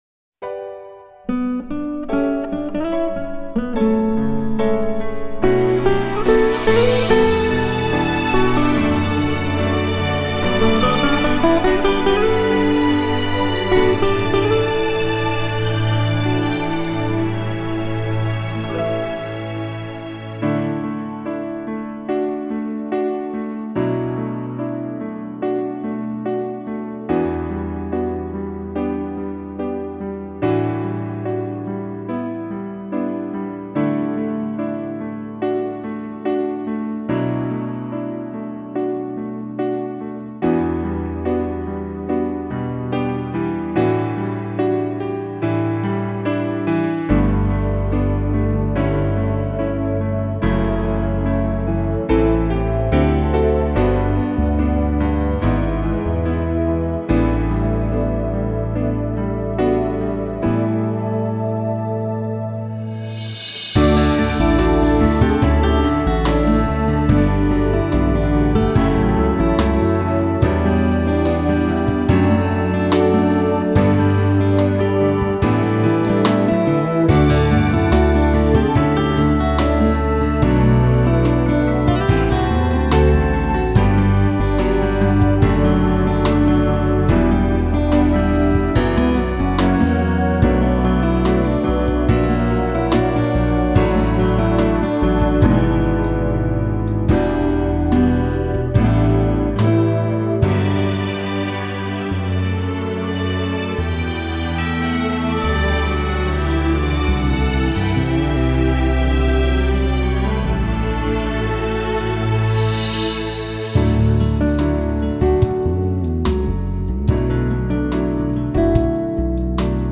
(original karaoke)